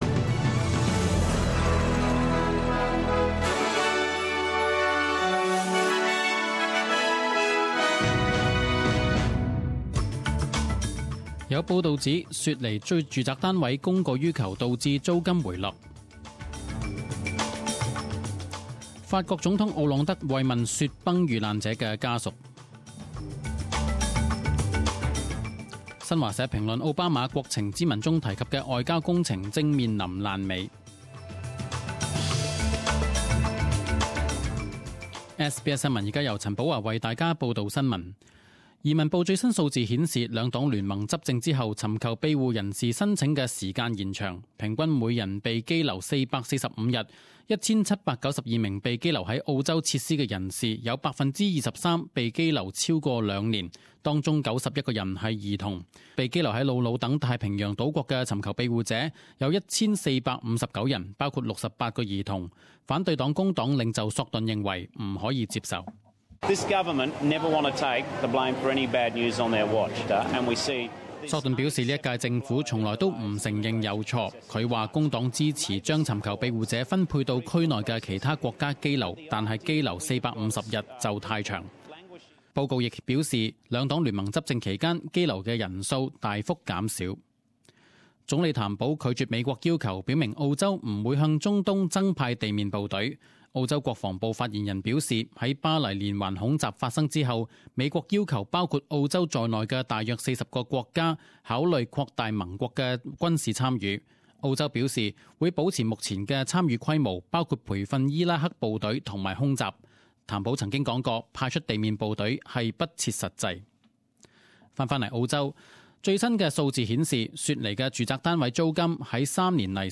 十点钟新闻报导（一月十四日）